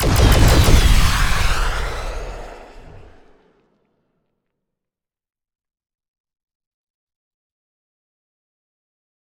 CosmicRageSounds / ogg / ships / combat / weapons / salvomisf.ogg